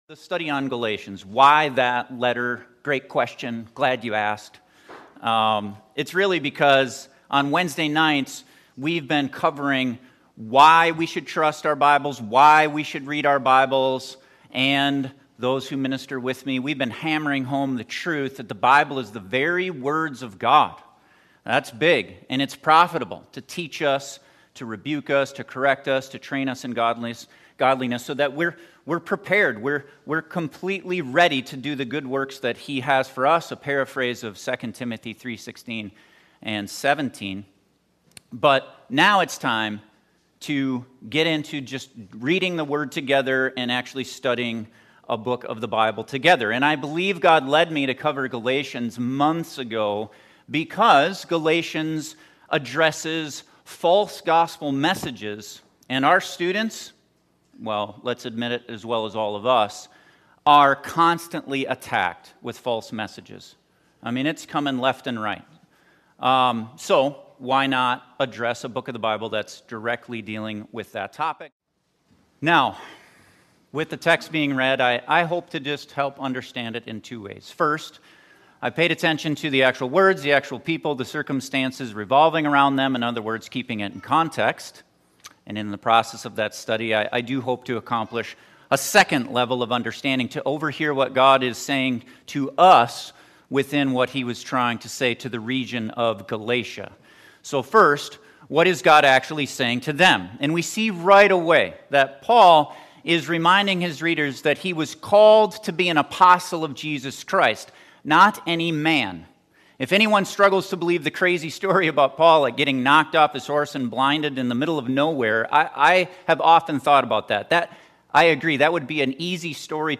In this introductory sermon on the book of Galatians we hear how important it is to fight for the purity of the biblical gospel message and what that looks like.